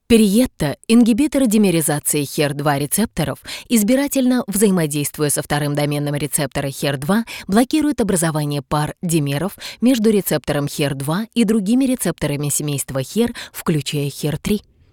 • Голос: Сопрано
• Женский
• Высокий
Закадровый текст - Технический текст